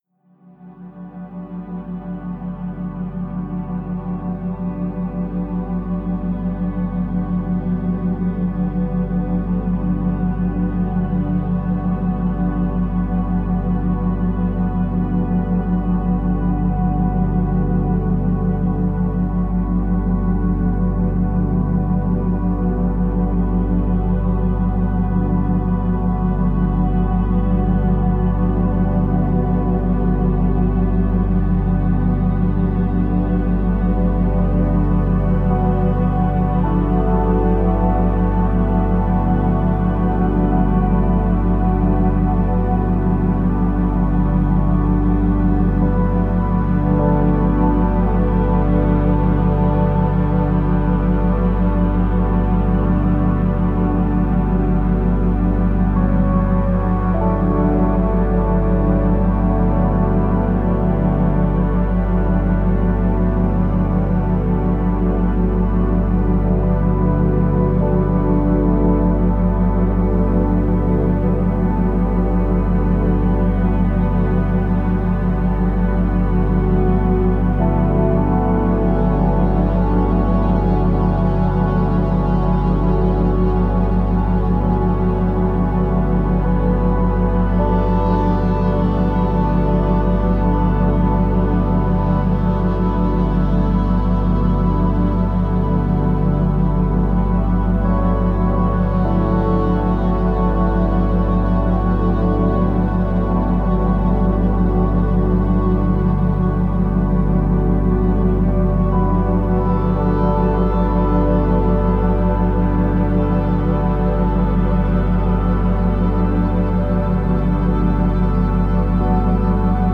méditation binaurale: battements binauraux pour une relaxation profonde
Les battements binauraux sont un phénomène psychoacoustique qui se produit dans le cerveau lorsque différents sons sont transmis dans l'oreille droite et dans l'oreille gauche à une amplitude constante. Des sons dont la fréquence diffère légèrement.